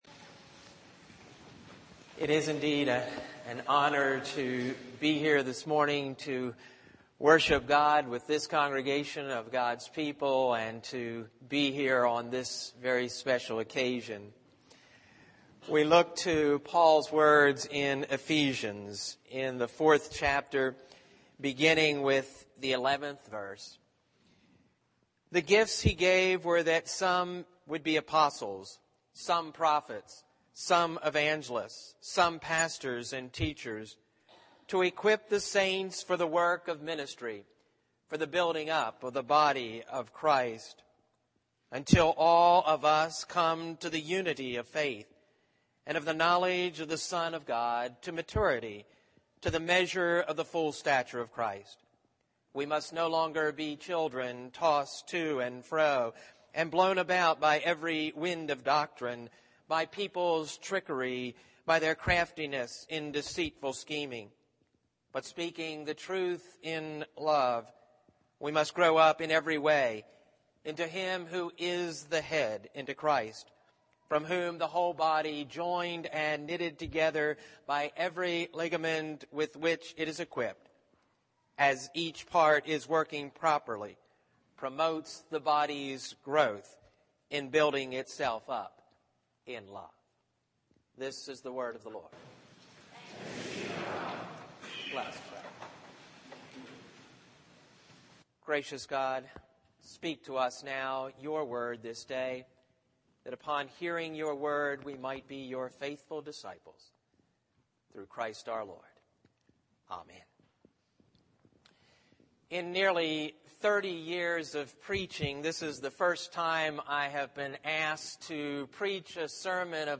worship_jan06_sermon.mp3